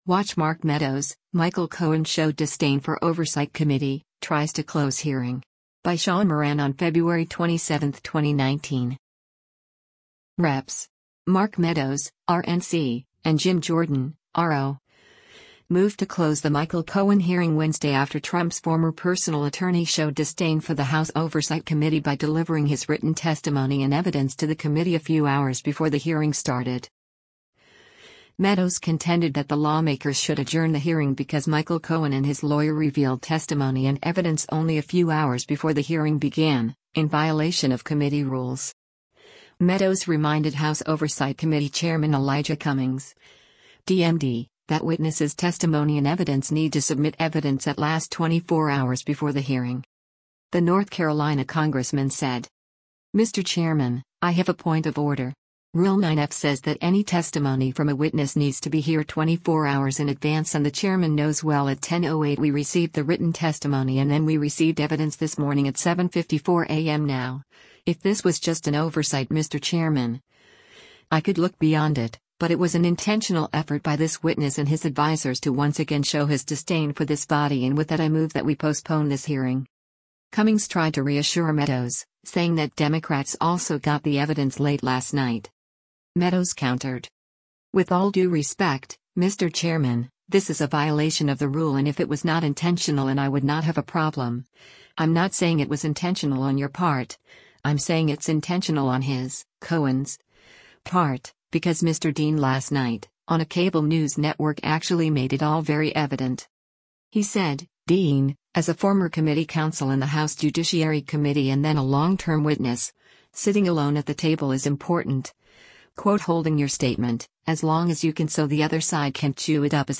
Mr. Chairman, I have a point of order.